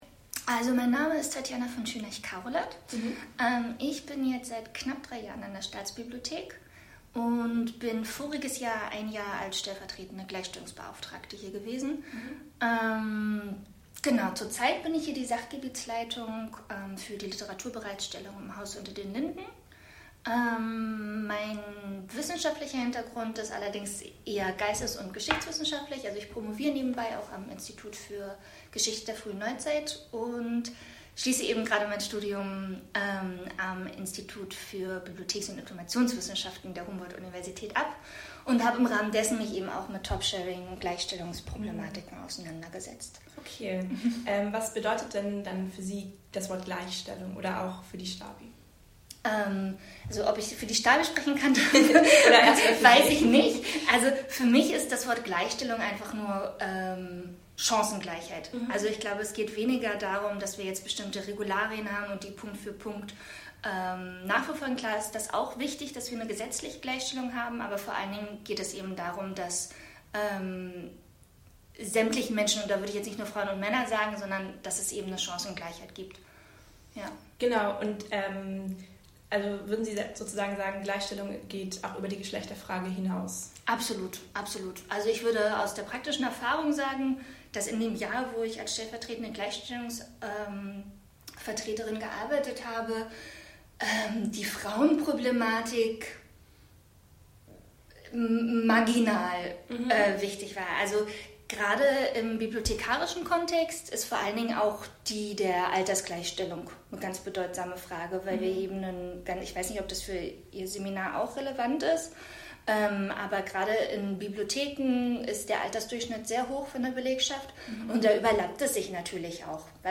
Interview-Stabi.mp3